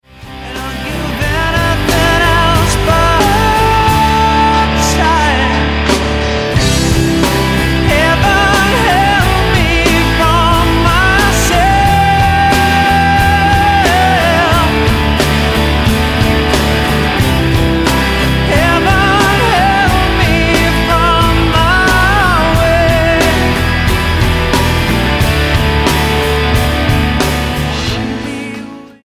Rock EP